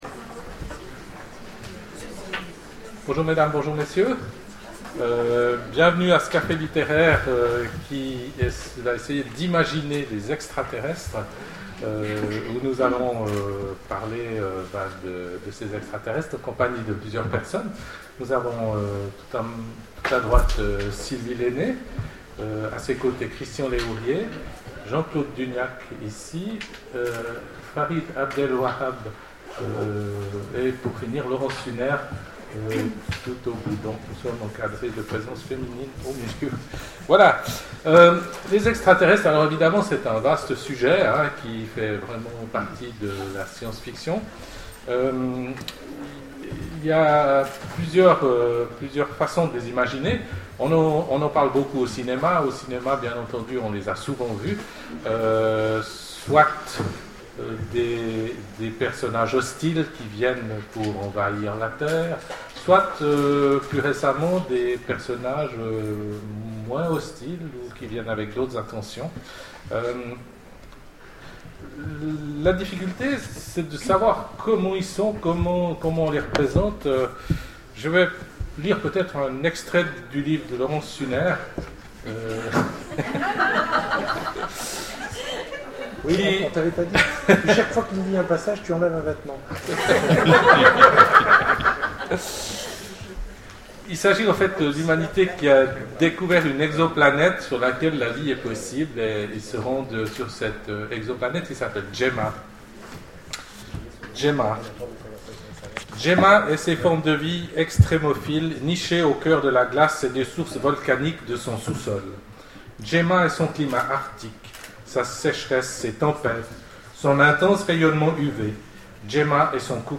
Imaginales 2012 : Conférence Imaginer les extraterrestres